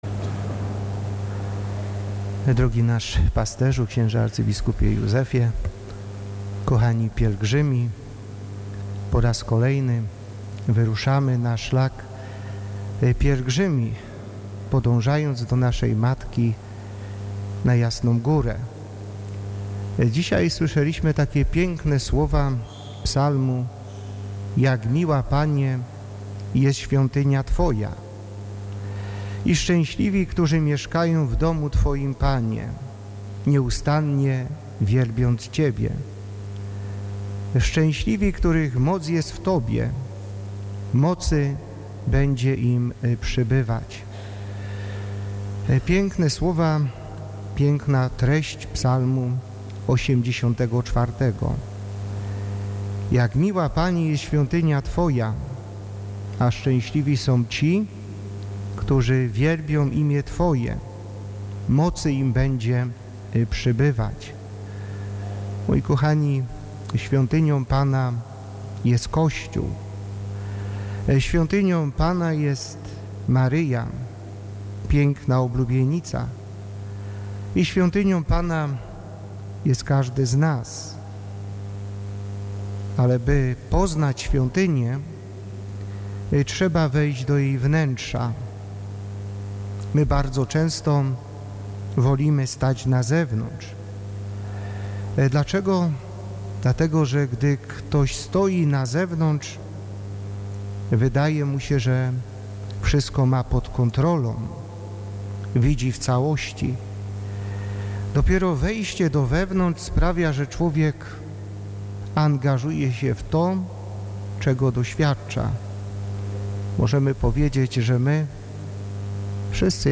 Ojciec biskup Jacek Kiciński wygłosił kazanie w trakcie Mszy św. w katedrze wrocławskiej na rozpoczęcie 43. Pieszej Pielgrzymki Wrocławskiej na Jasną Górę.